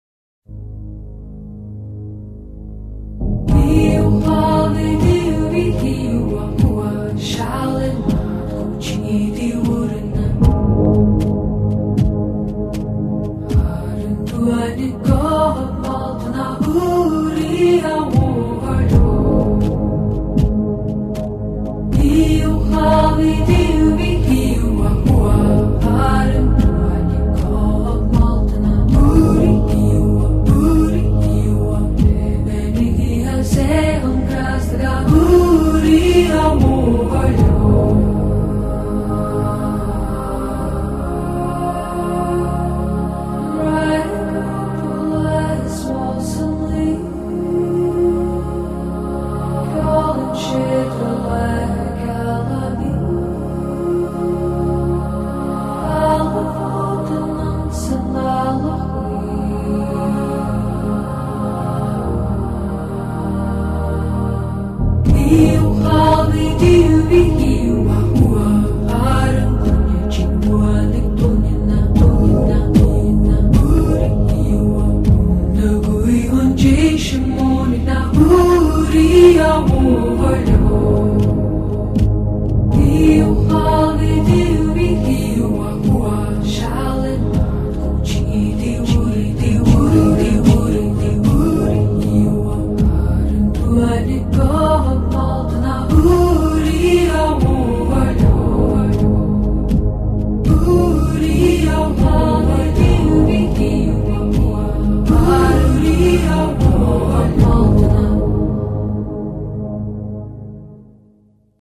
以民谣的旋律为主，电子合成器的意境营造为辅，衬以古典音乐的思维